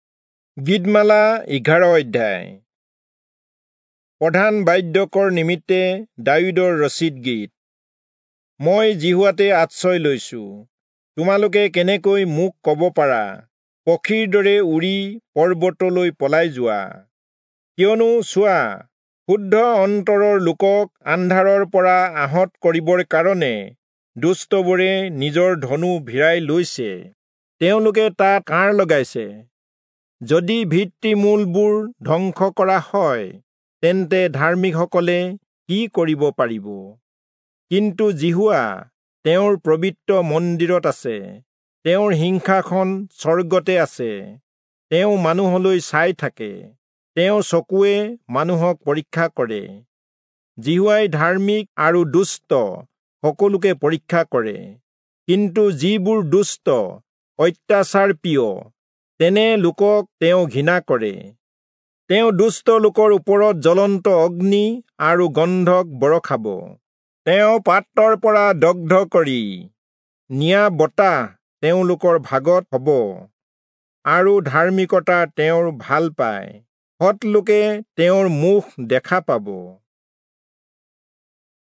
Assamese Audio Bible - Psalms 108 in Irvbn bible version